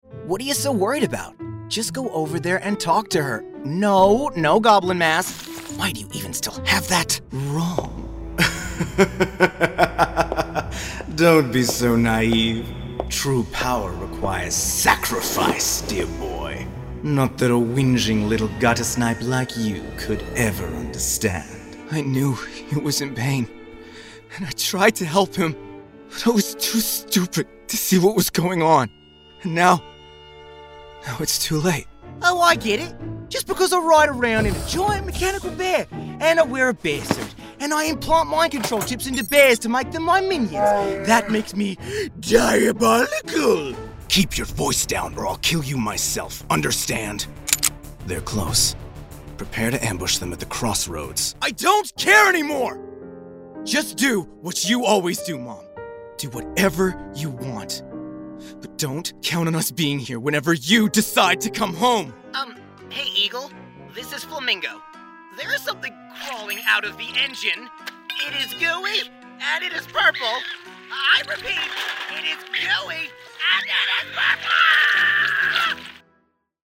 Demo
Teenager, Young Adult, Adult
Has Own Studio
australian | natural
british rp | natural